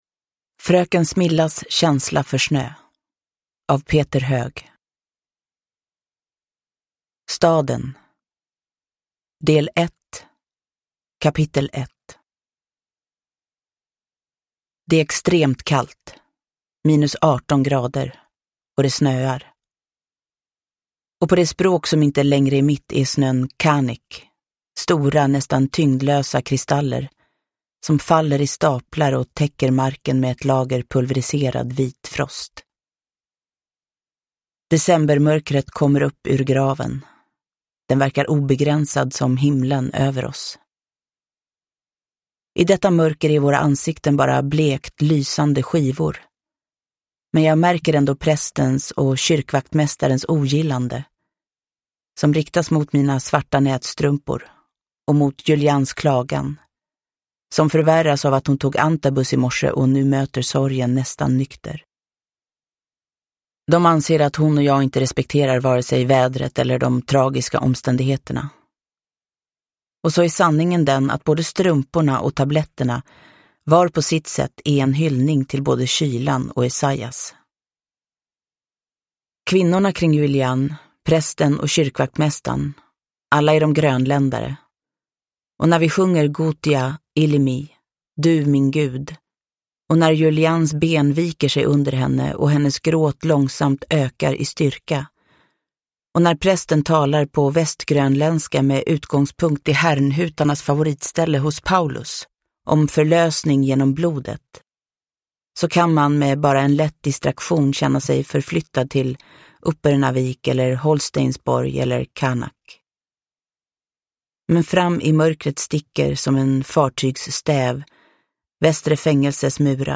Fröken Smillas känsla för snö – Ljudbok – Laddas ner
Uppläsare: Jessica Liedberg